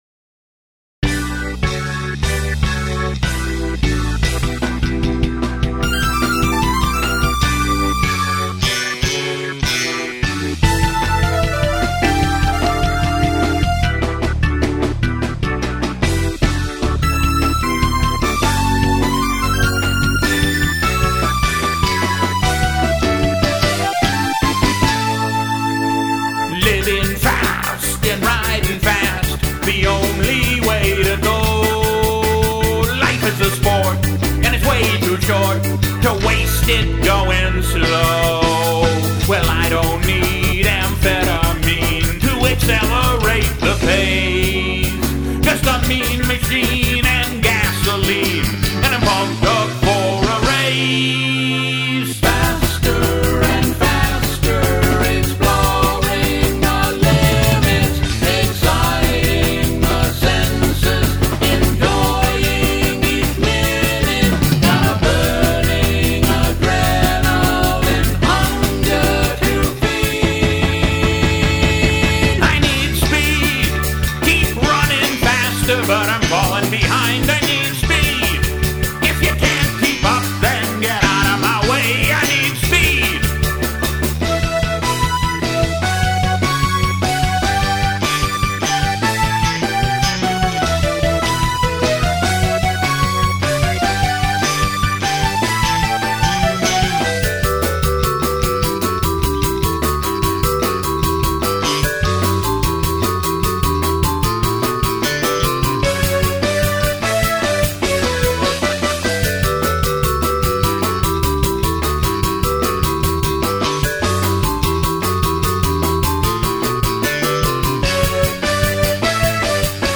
This song also contains my first solo.